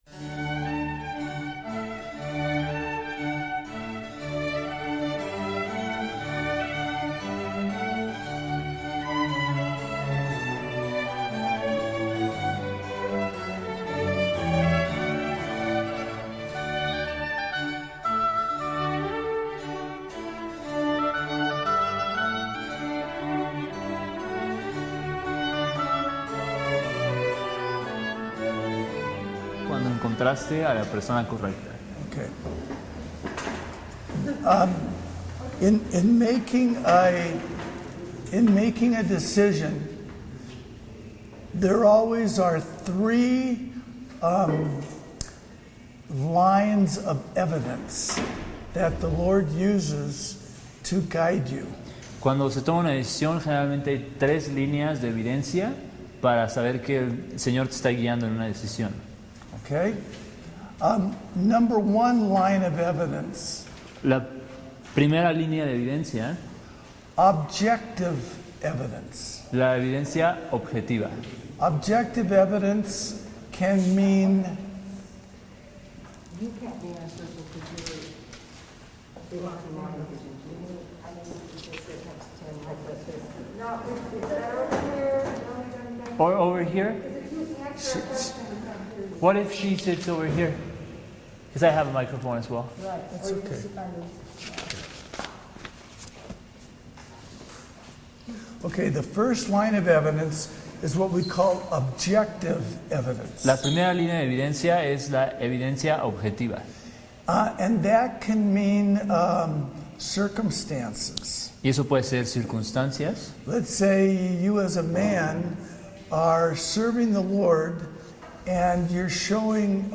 Conferencia Matrimonio 6